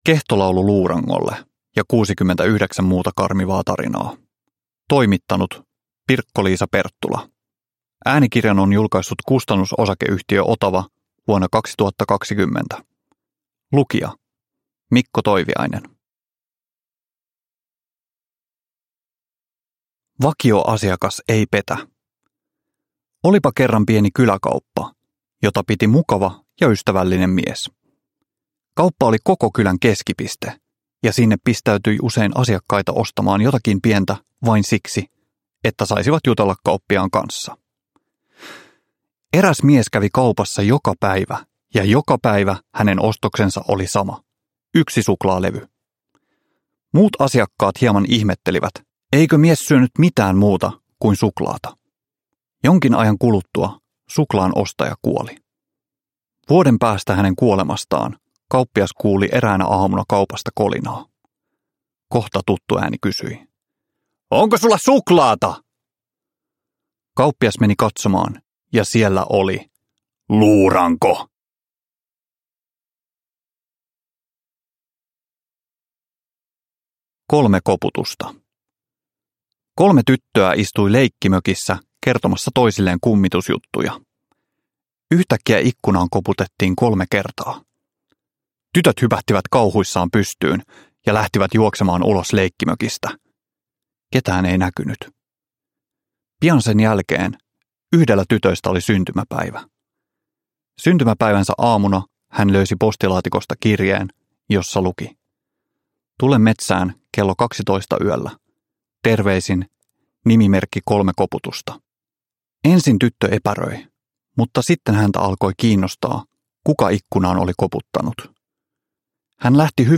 Kehtolaulu luurangolle ja 69 muuta karmivaa tarinaa – Ljudbok – Laddas ner